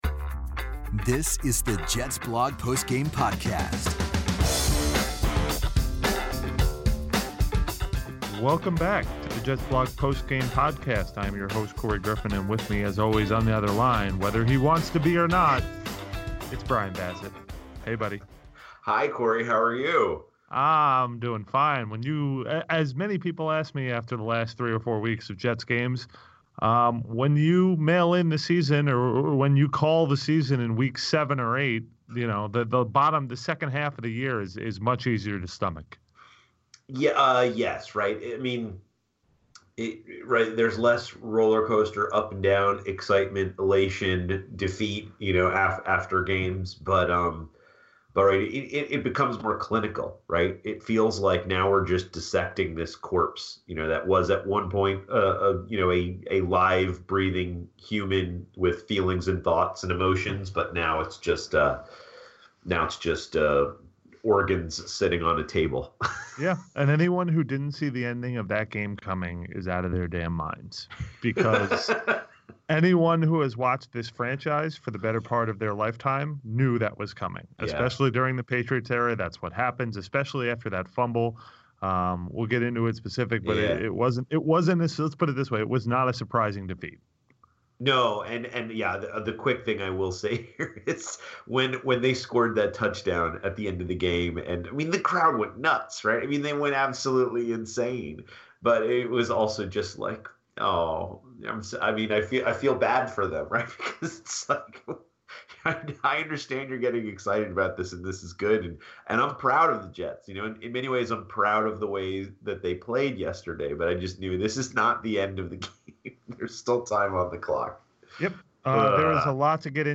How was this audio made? They sift through another week of your voice mails, as the Jets sort through what's left of their season.